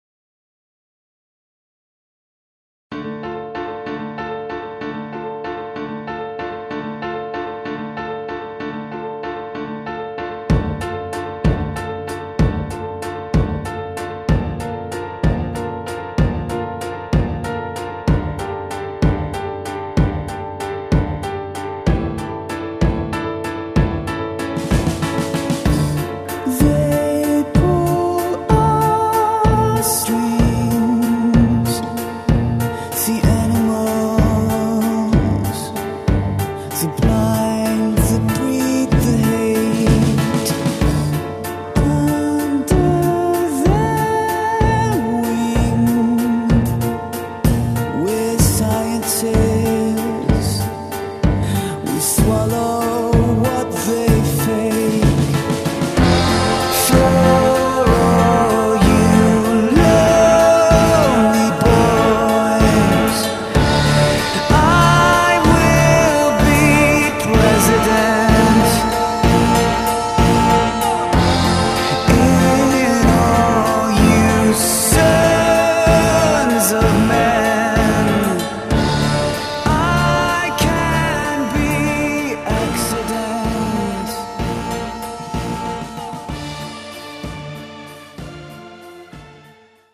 Schwermütig, dunkel, tanzbar, hymnisch.